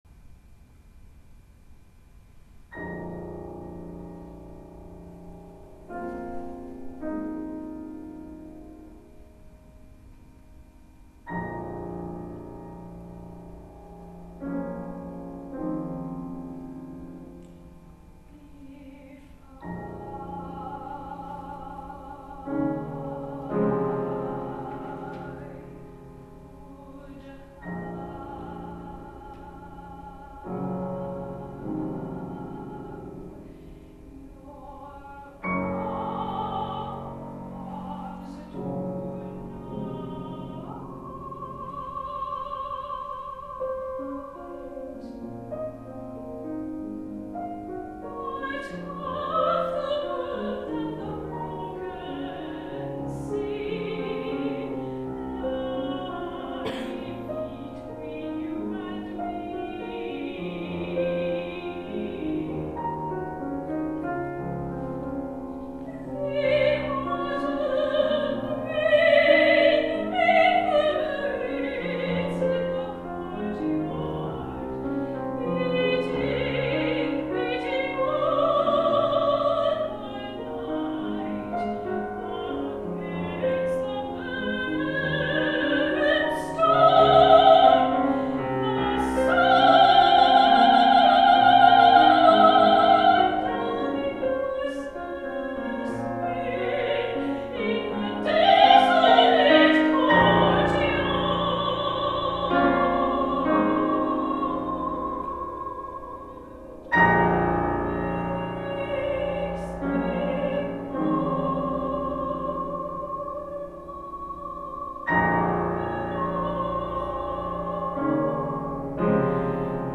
Voice and Piano (High and Medium version)